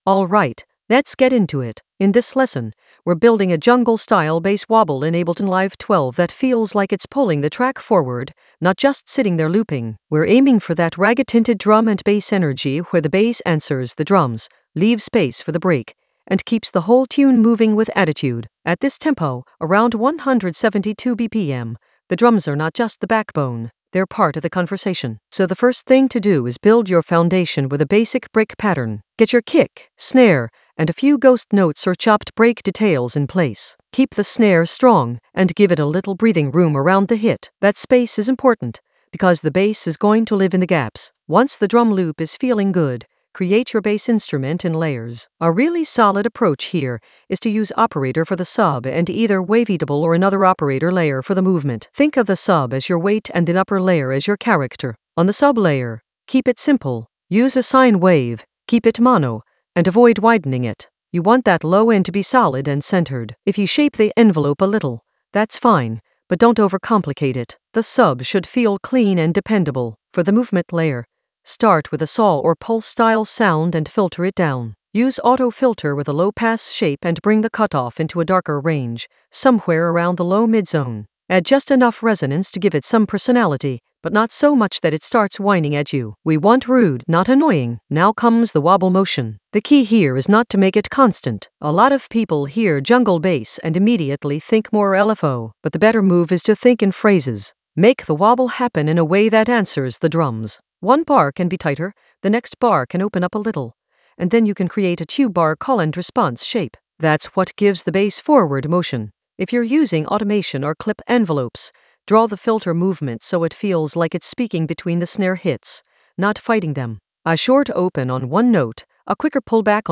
An AI-generated intermediate Ableton lesson focused on Think jungle bass wobble: pull and arrange in Ableton Live 12 in the Ragga Elements area of drum and bass production.
Narrated lesson audio
The voice track includes the tutorial plus extra teacher commentary.